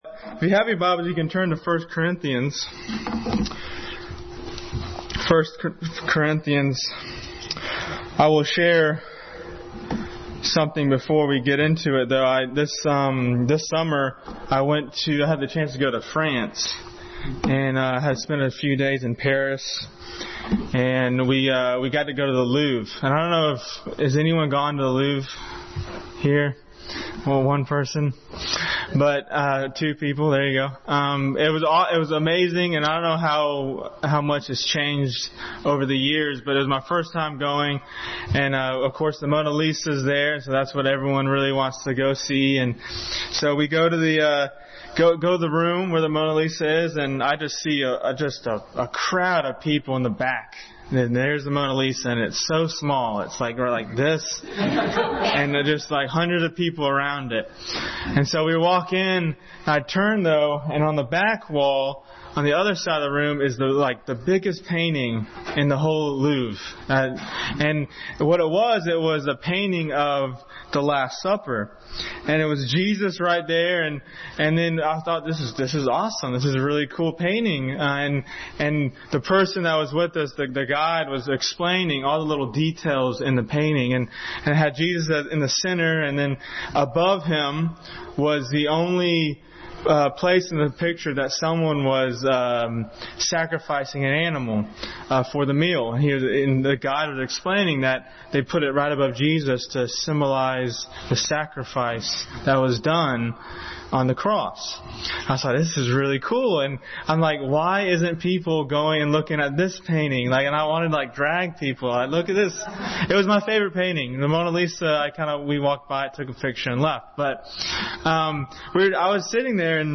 Passage: 1 Corinthians 8:1-13, 13:1-8, Hebrews 12:1-2, 2 Corinthians 5:2, Ephesians 5:2 Service Type: Family Bible Hour